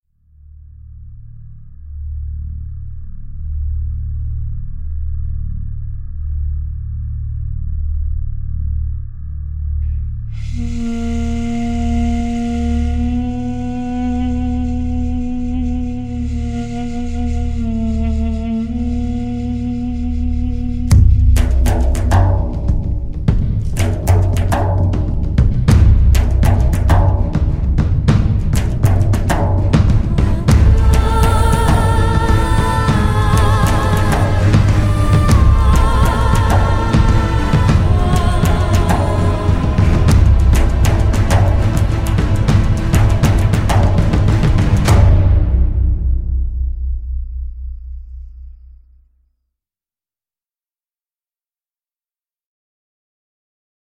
Artist: Instrumental,